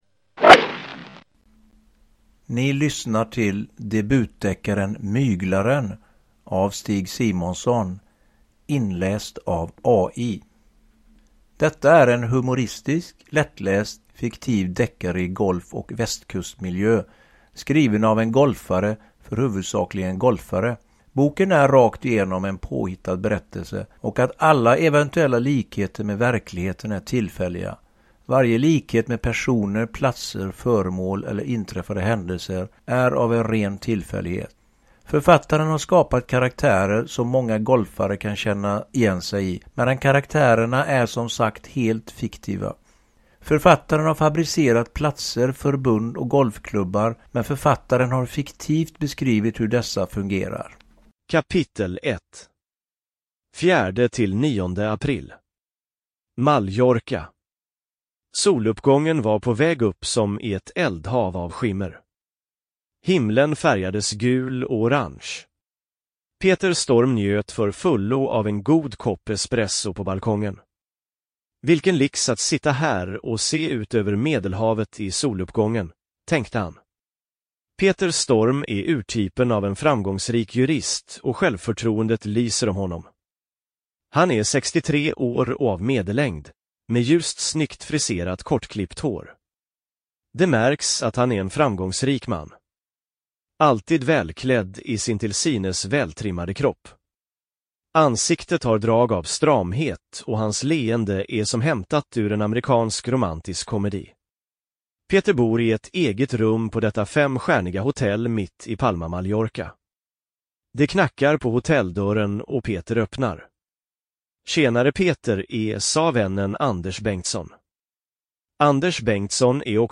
Uppläsare: AI Voice
Ljudbok